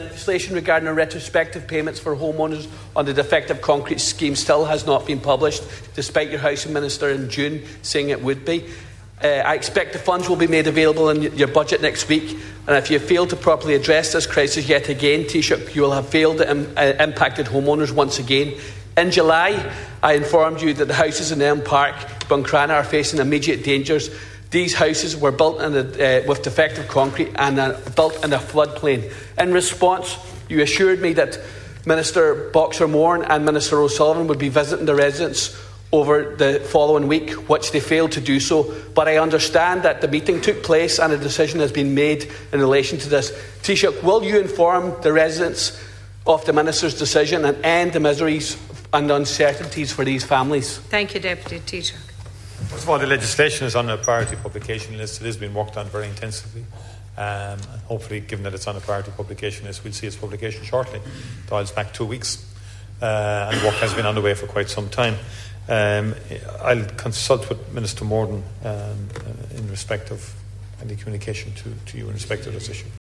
The Dail has been told that if retrospective payments for defective block homeowners are not included in the budget next week, it will be a major failing on the part of the government.
Raising the issue, Deputy Charles Ward also asked the Taoiseach about affected homes at Elm Park in Buncrana, which owners want to re-build elsewhere.